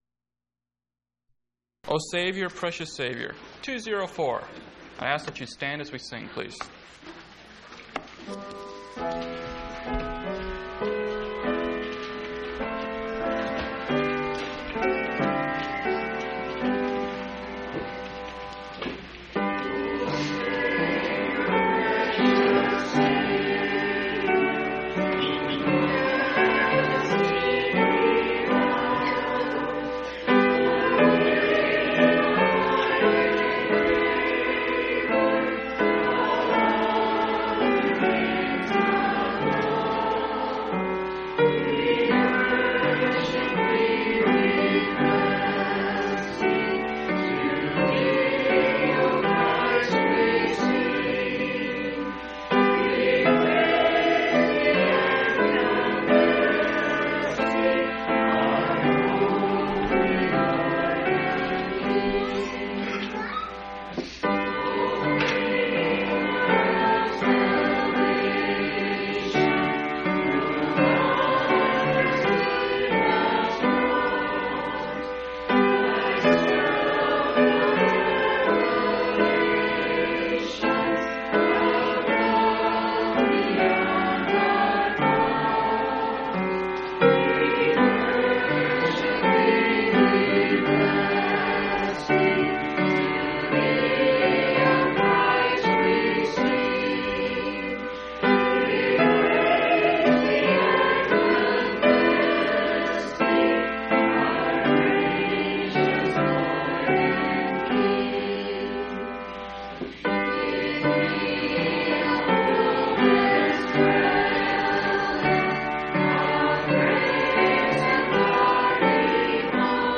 3/26/1995 Location: Phoenix Local Event